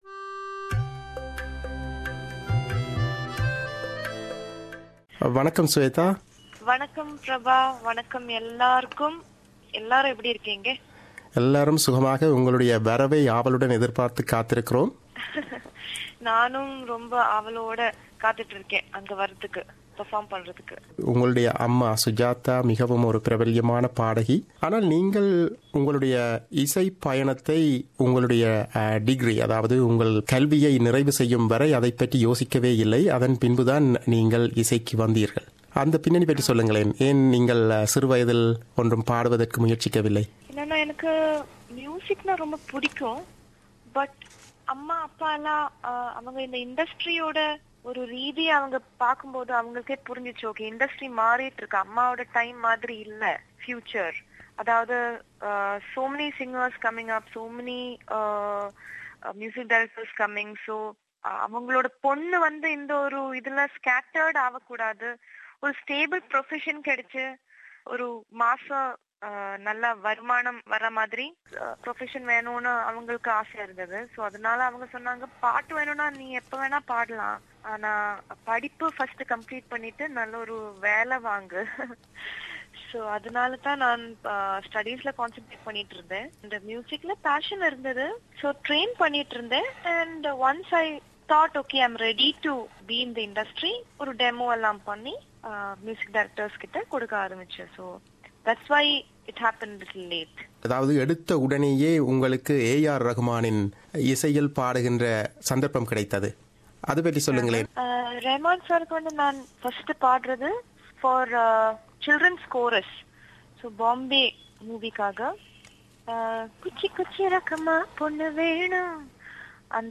Playback singer Shweta Mohan singing for SBS Tamil